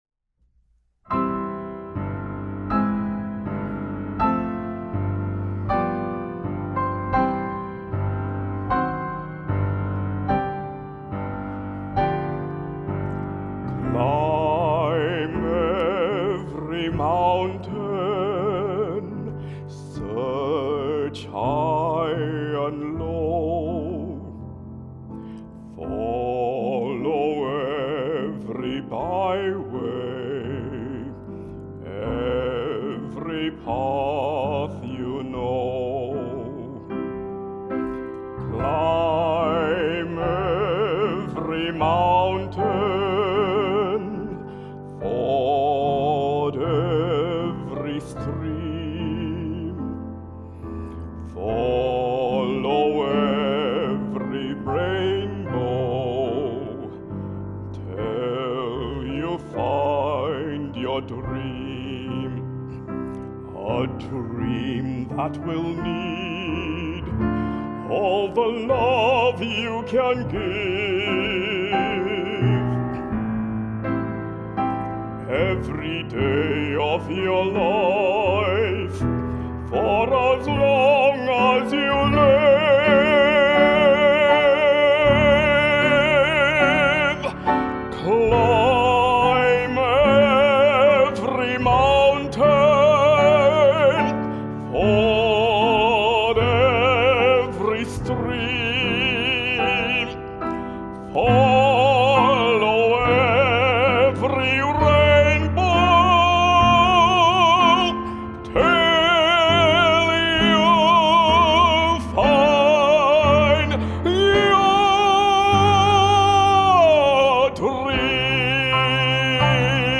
Soprano
Piano Accompanist